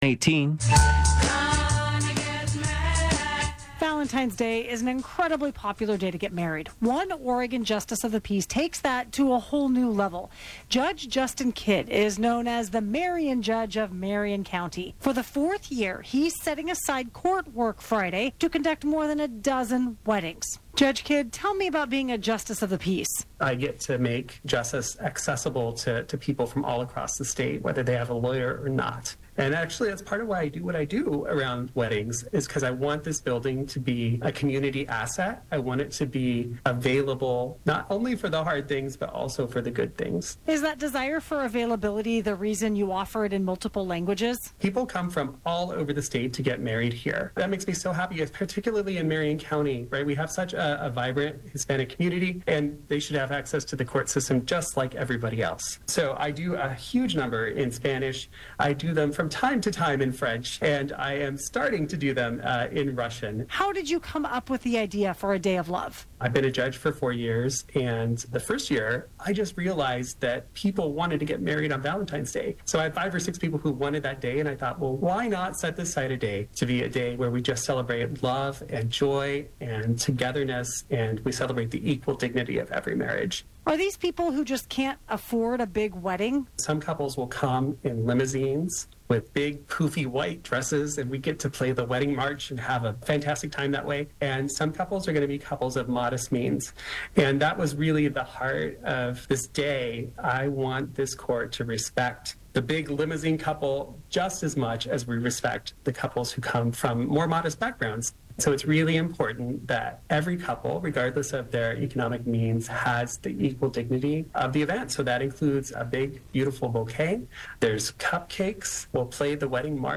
Justin Kidd, Marion County Justice of the Peace, on KXL Radio (MP3)